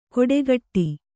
♪ koḍegaṭṭi